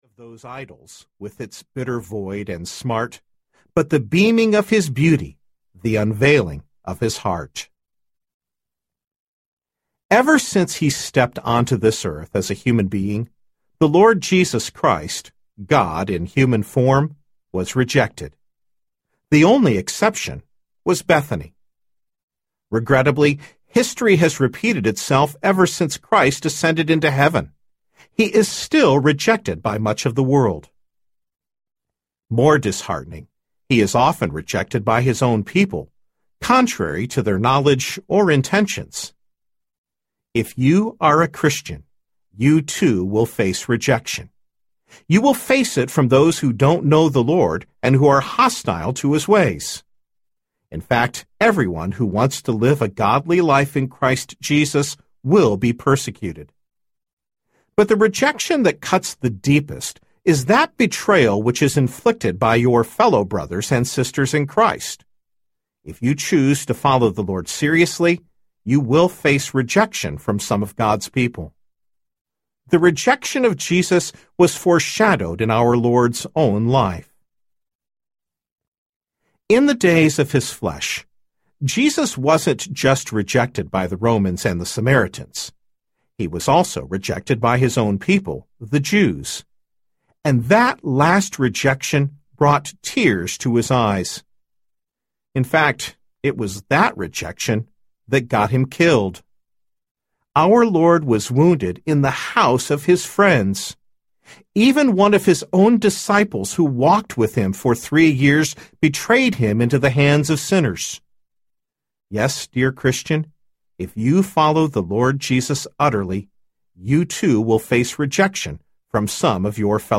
God’s Favorite Place on Earth Audiobook
4 Hrs. – Unabridged